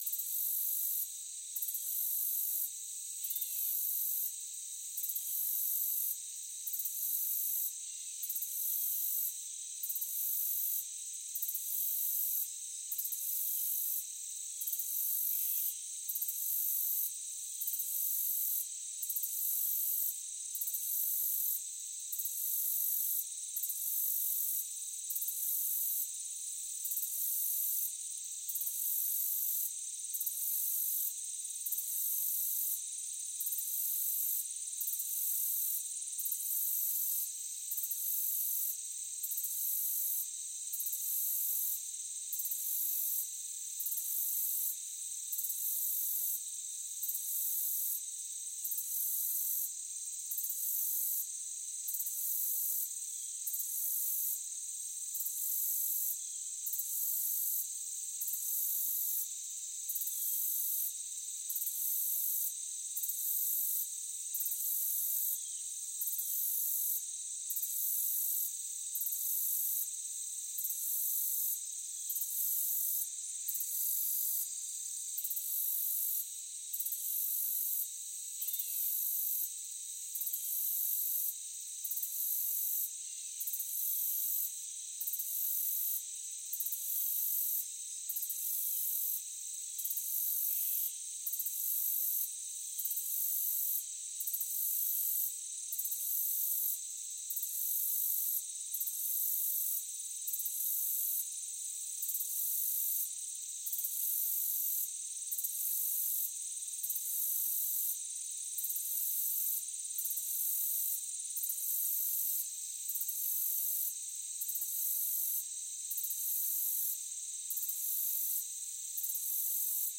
Шум летнего луга с насекомыми